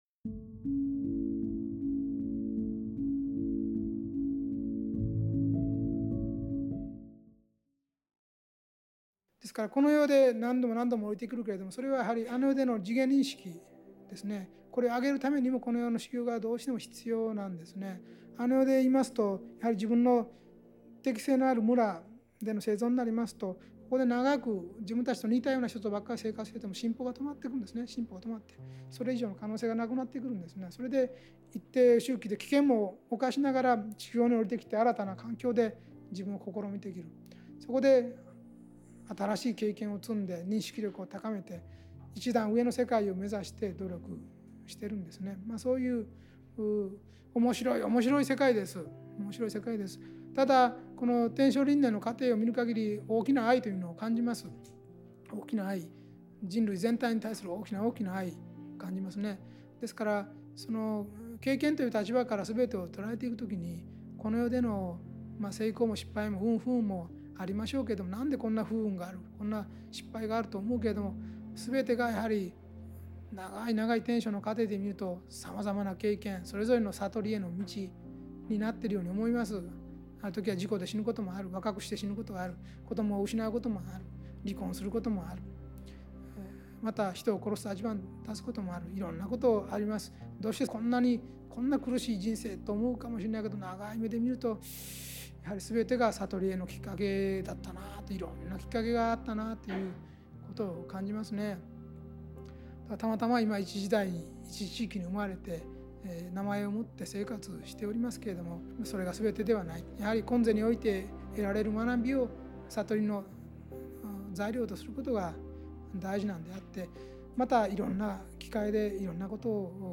ラジオ番組「天使のモーニングコール」で過去に放送された、幸福の科学 大川隆法総裁の説法集です。
大川隆法総裁「修行の王道とは何か」より